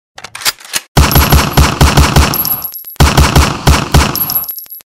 جلوه های صوتی
دانلود صدای شلیک کلت پشت سر هم از ساعد نیوز با لینک مستقیم و کیفیت بالا